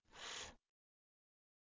子音/f/ は上の前歯と下唇の間から空気が摩擦して出る「唇歯摩擦音（しんし まさつおん）」という音です。声帯は振動させない無声音なので、声ではなく息もしくは空気のような音になります。
子音/f/のみの発音
子音fのみの発音.mp3